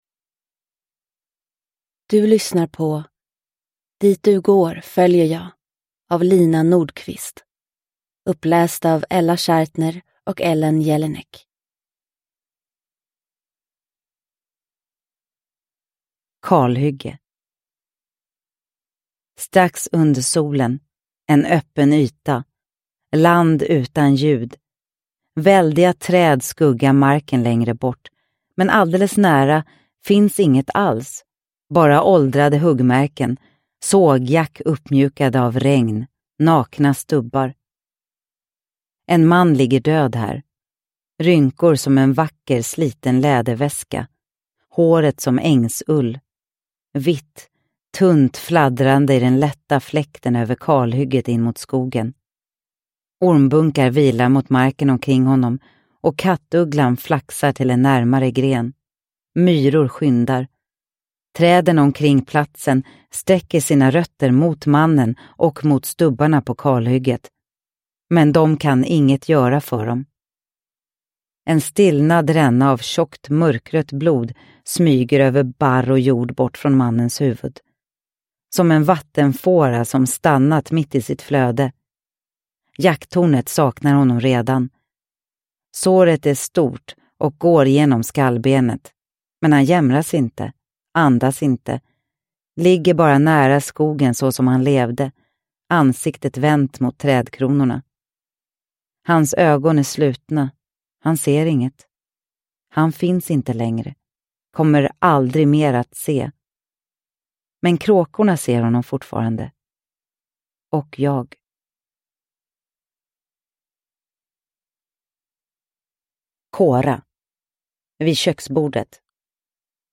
Dit du går, följer jag – Ljudbok – Laddas ner